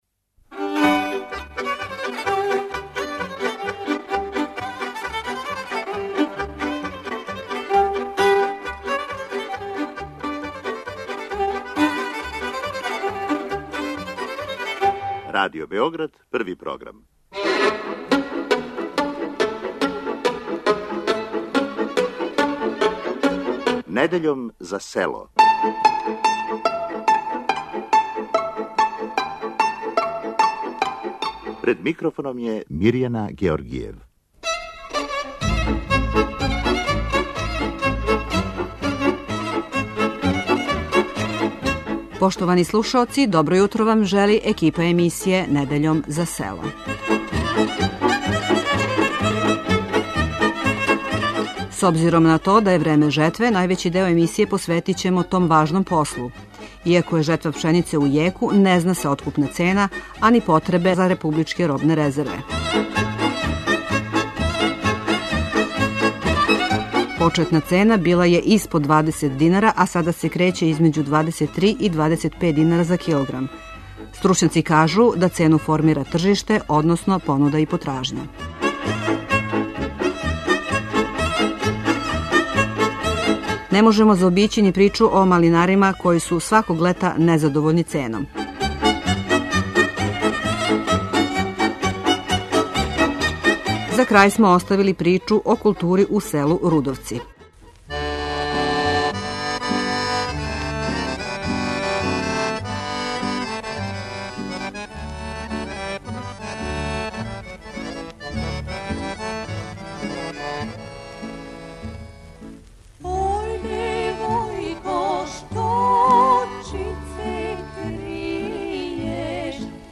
Чућете најмлађе чланове КУД Рудовци. Највећи део емисије, ипак, биће посвећен жетви, која је у току.